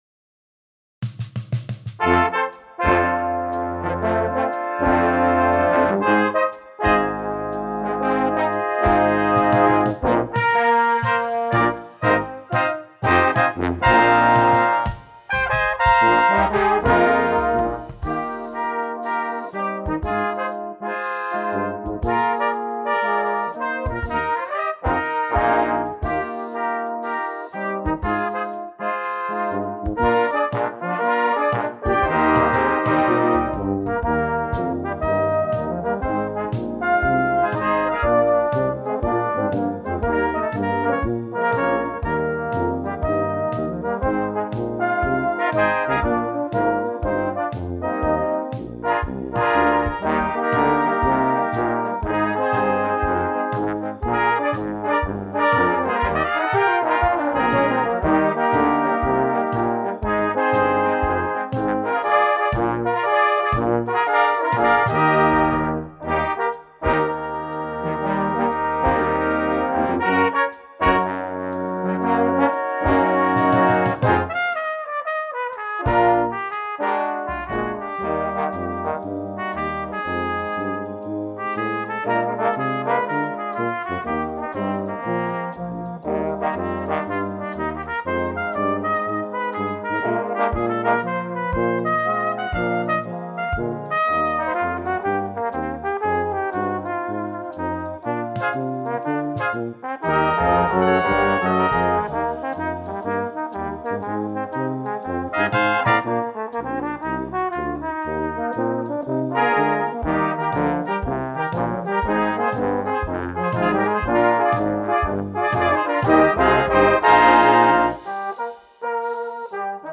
Composition Style: Swing Arrangement